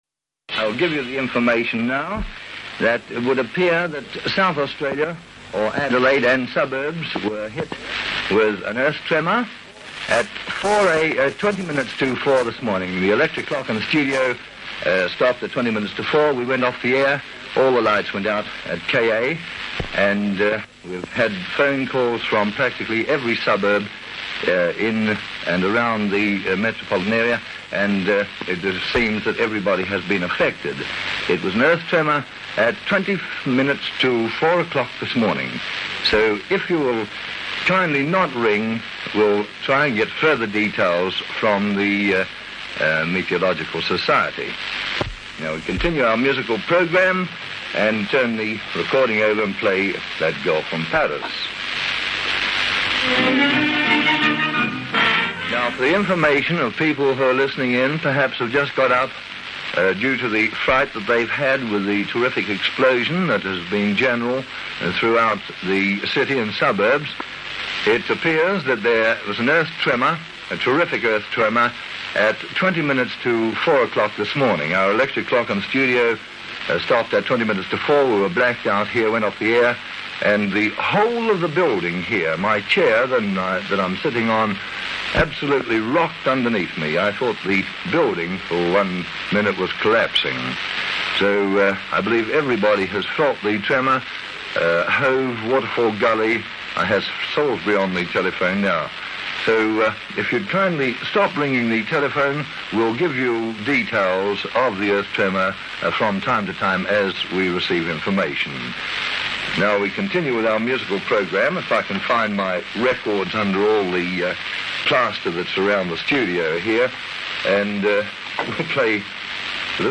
5KA Adelaide 1954 Earthquake Aircheck
5KA-Adelaide-1954-Earthquake-Aircheck.mp3